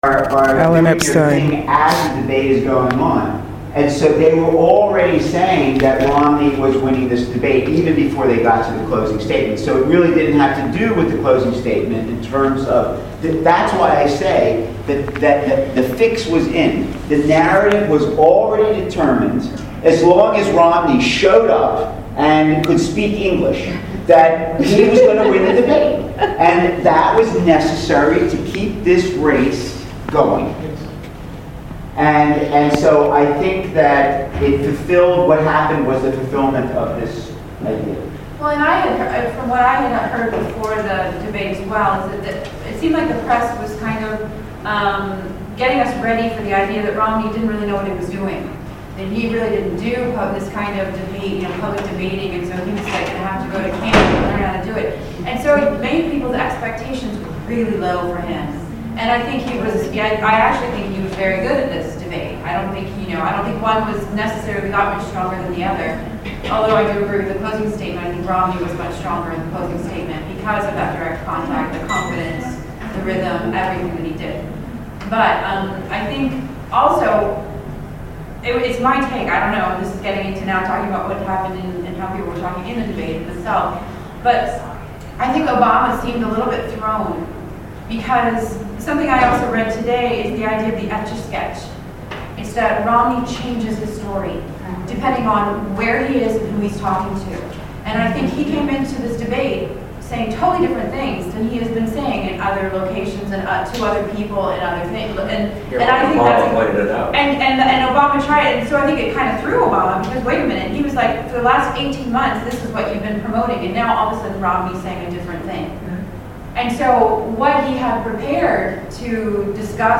gave us him commentary and answered our questions